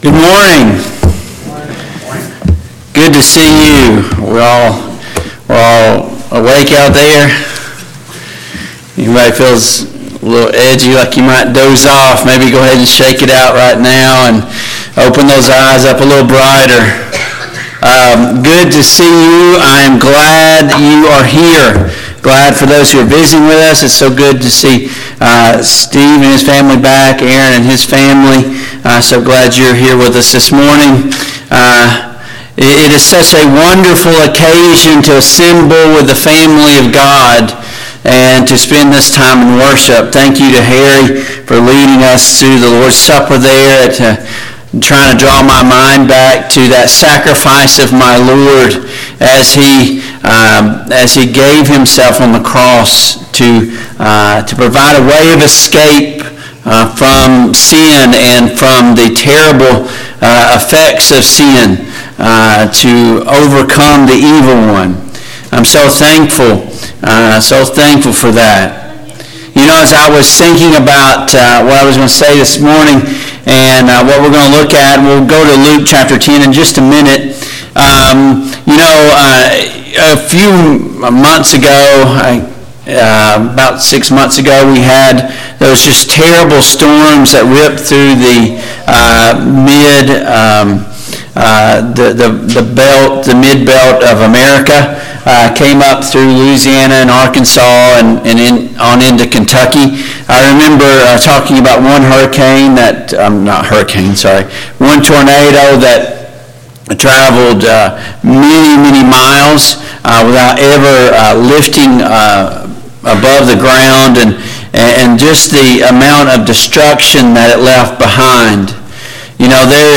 Service Type: AM Worship Topics: Forgiveness , Meekness , Righteousness , Submission